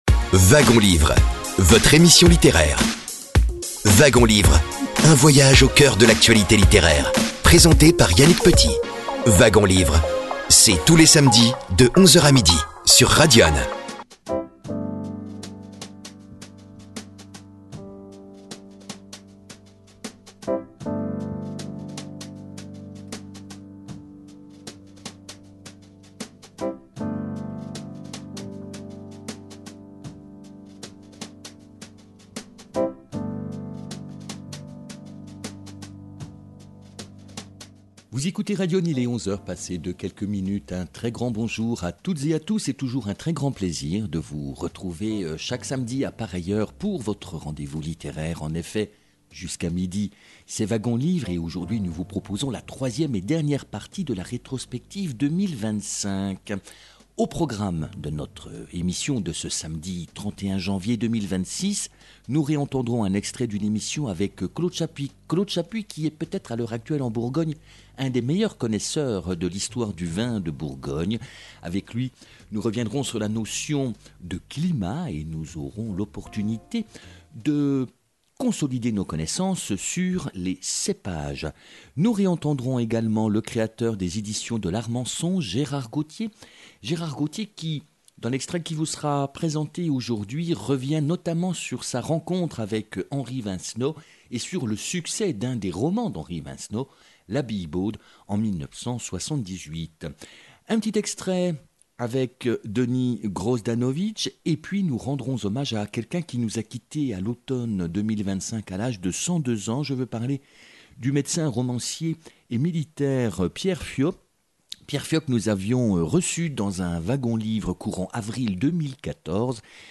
Nous vous proposons des extraits d’émissions diffusées durant 2025 sur l’antenne de Radyonne FM.